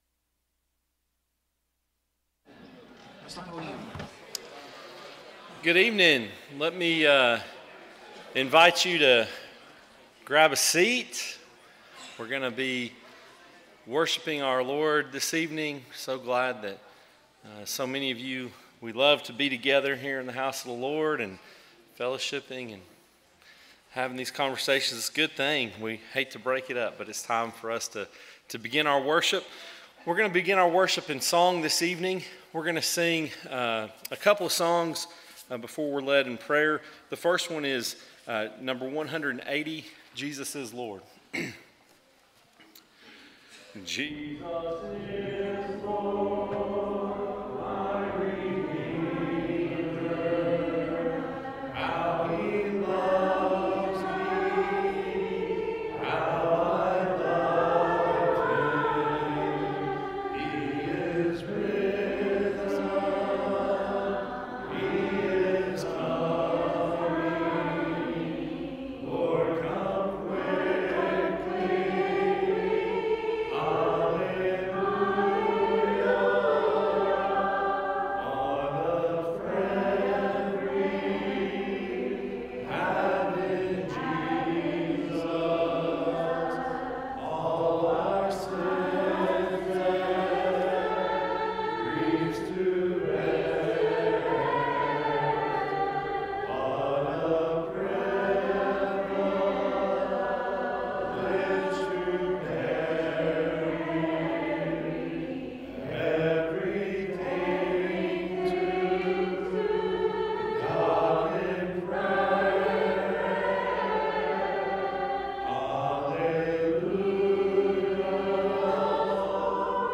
Psalm 94:19, English Standard Version Series: Sunday PM Service